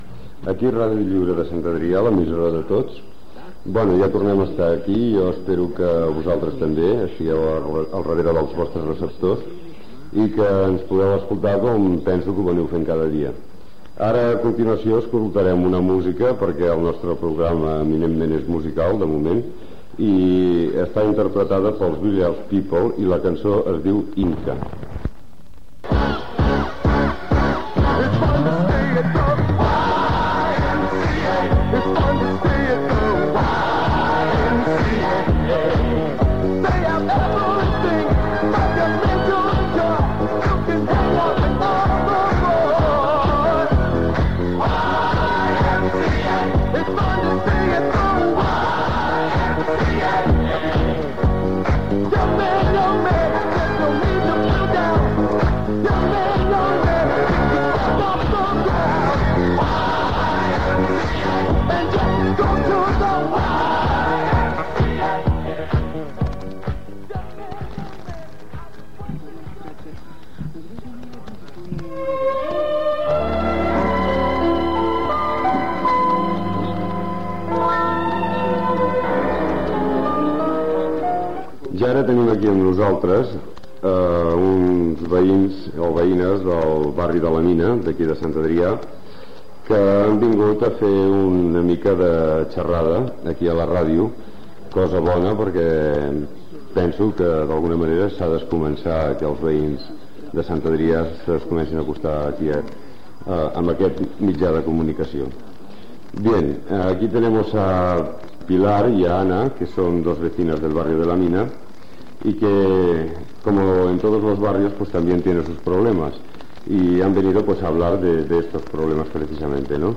Tema musical i veïnes de La Mina
Entreteniment
FM